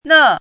汉字“讷”的拼音是：nè。
“讷”读音
讷字注音：ㄋㄜˋ
国际音标：nɤ˥˧
nè.mp3